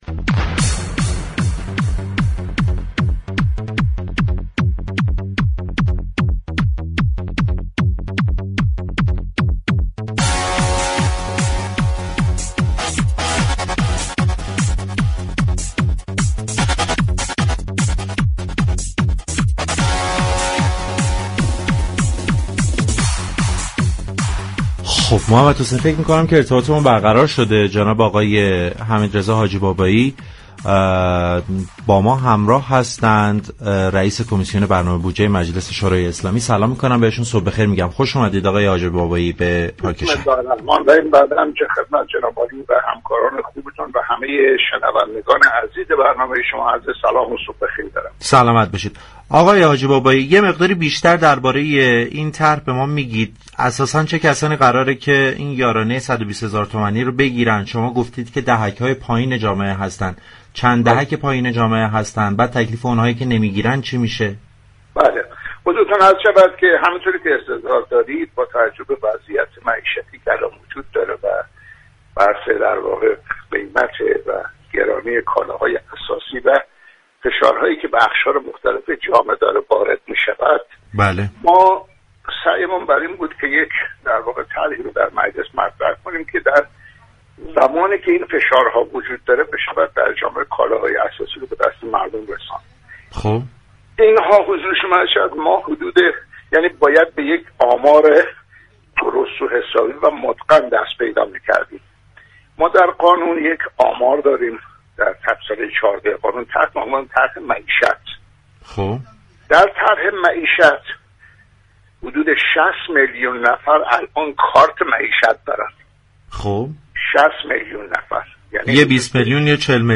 حمیدرضا حاجی بابایی رئیس كمیسیون برنامه و بودجه مجلس شورای اسلامی در گفتگو با پارك شهر گفت: طرح كالا برگ در راستای كمك به اقشار ضعیف به طور مستقل انجام می شود و هیچ ارتباطی با ارز 4200 تومانی، یارانه ها و طرح معیشتی ندارد.